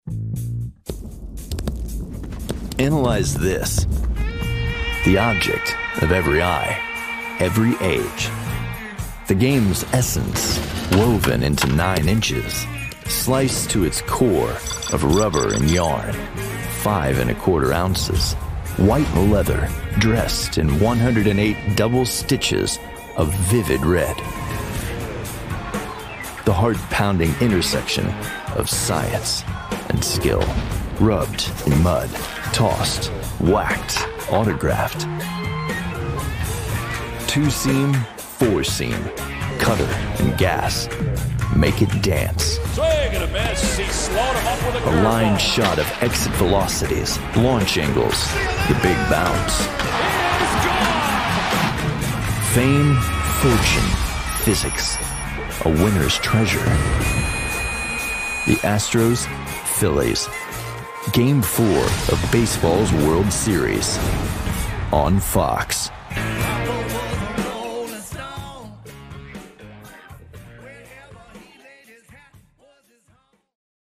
Middle Aged
Versatile , Unique , Relaxed , the guy next door , energetic , Intuitive , Adaptable , Expressive , Dynamic , Quick turn around , Takes direction very well!